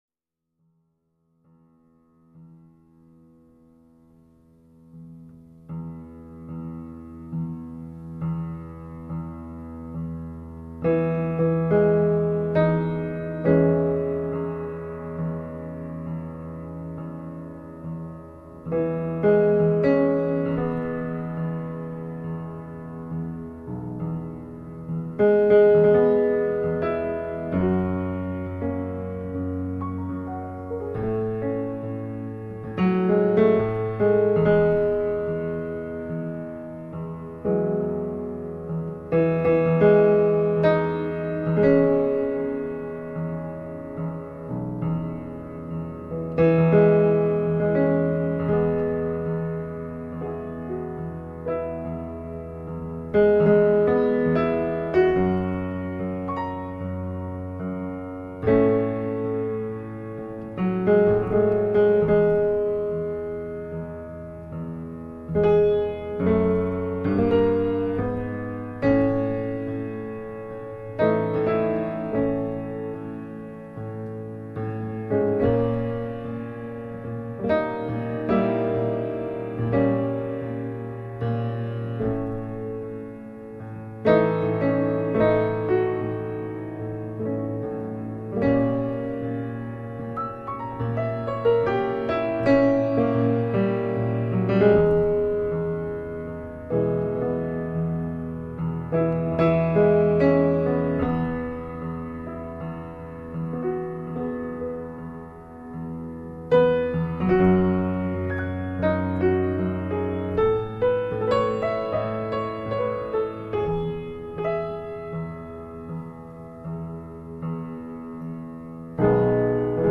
Trentacinque minuti di improvvisazione pianistica